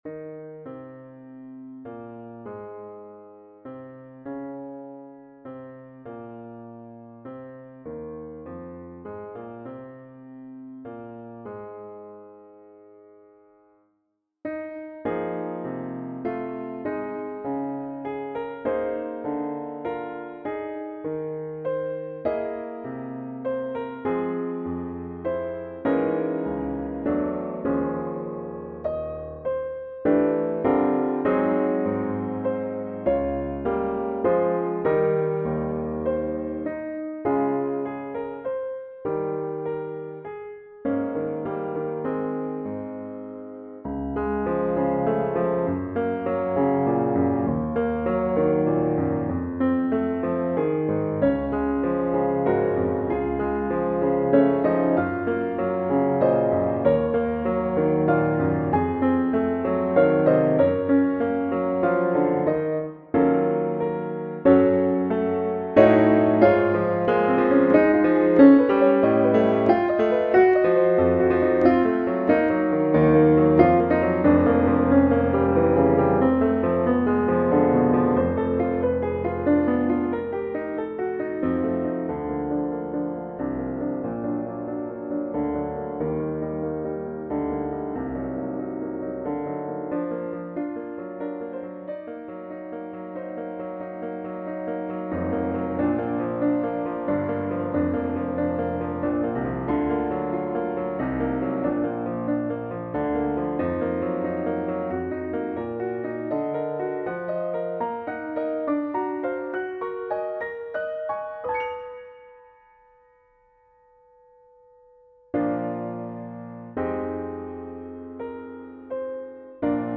arranged for Solo Piano.